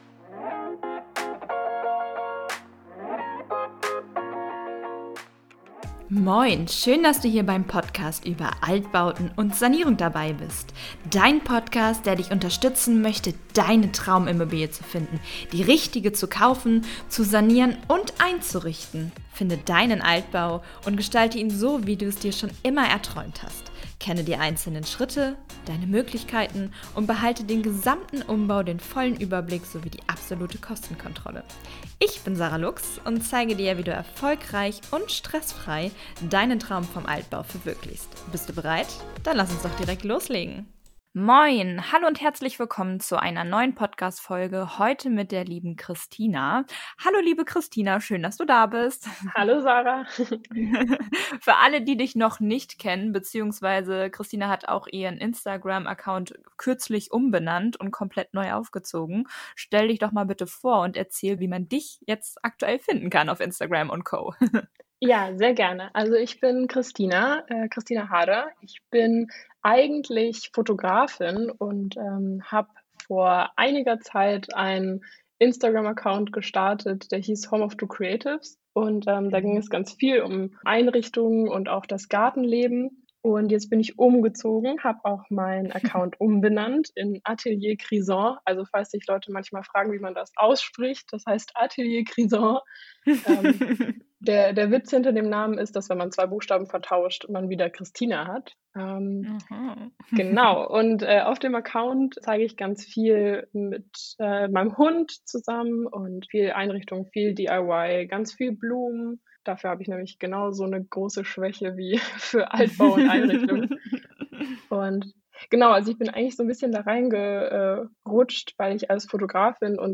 How-to-do-Altbau-Sanierung-und-Einrichtung-Atelier-Krisaint-Einrichtungsplanung-Kuechenplanung-Interview.mp3